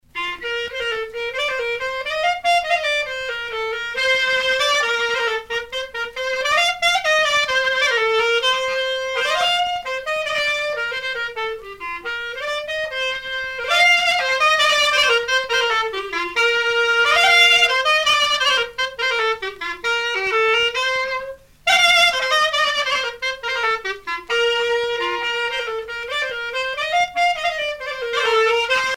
danse : gavotte bretonne
Pièce musicale éditée